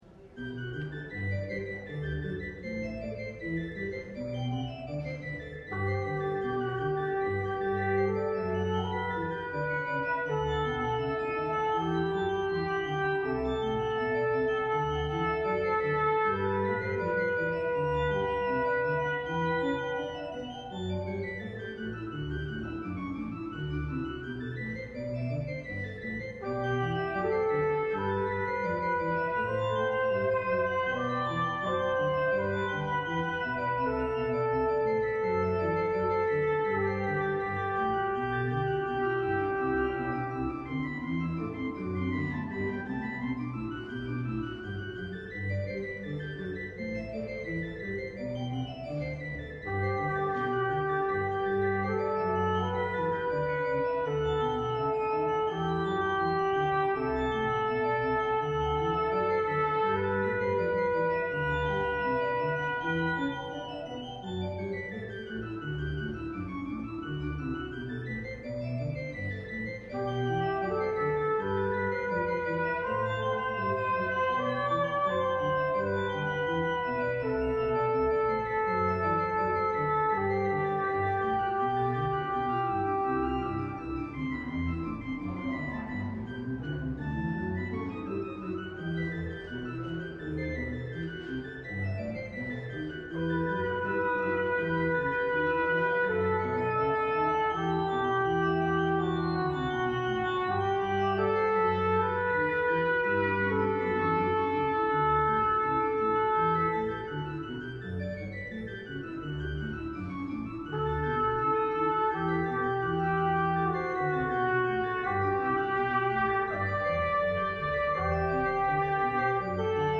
LIVE Morning Service - The Word in the Windows: Jesus’ First Miracle